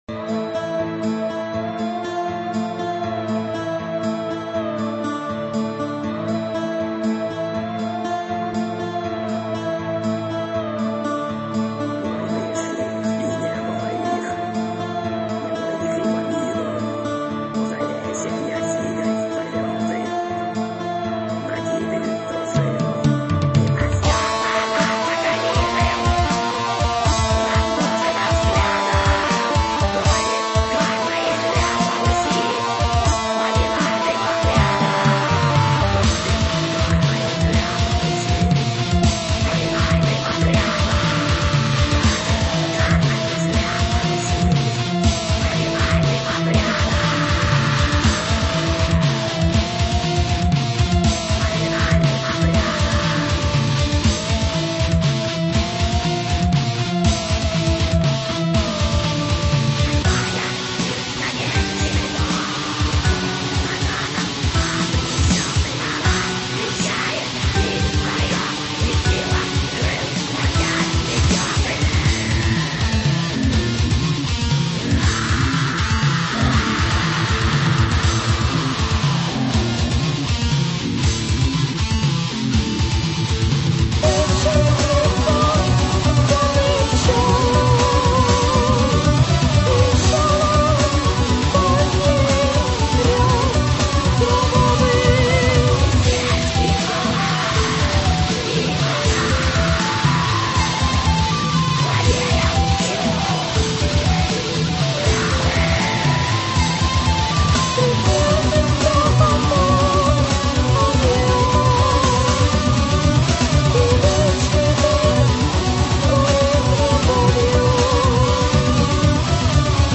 sympho pagan black metal
Вокал частично женский,чистый,частично мужской "нечистый"
из-за отвратного качества слова ваще не разобрать